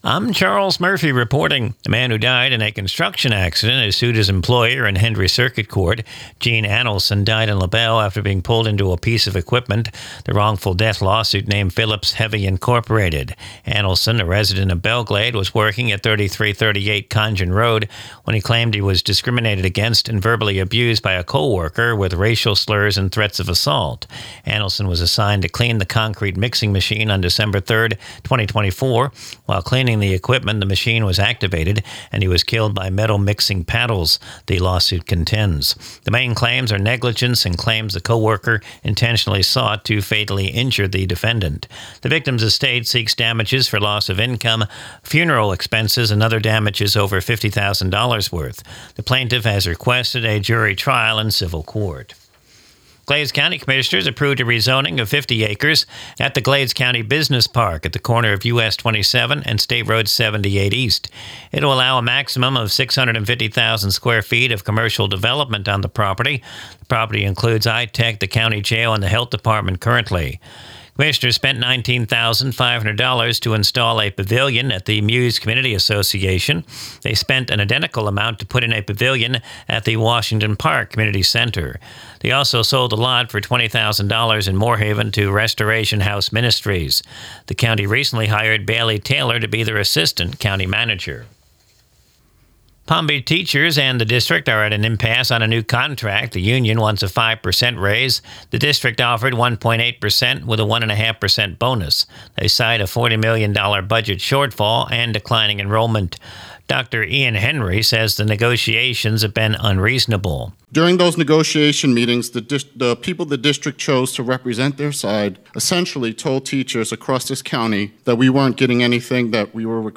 Recorded from the WAFC daily newscast (Glades Media).